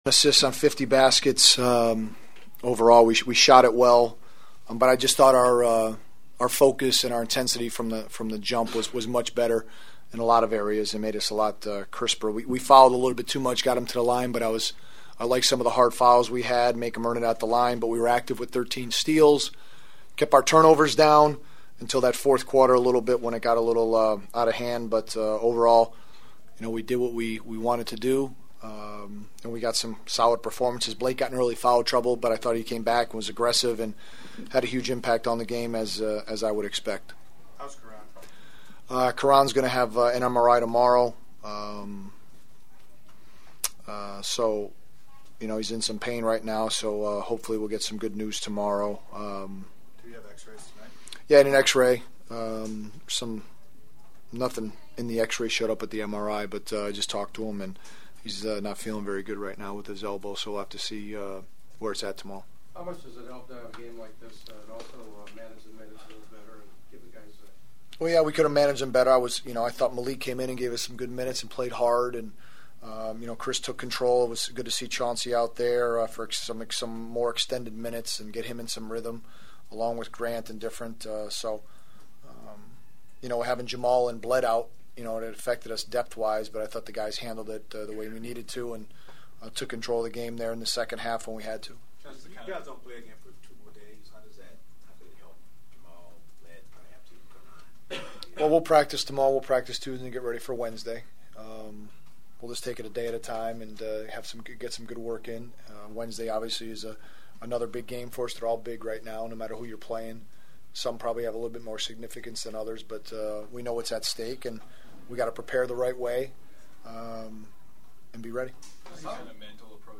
The following is my postgame locker room chatter with the winning Clippers who are now an impressive 25 games over .500 before hosting Memphis on Wednesday night (which you can always hear on KFWB Newstalk 980).
Clippers coach Vinny Del Negro gives us his take on the blowout win and a look ahead to the Memphis game: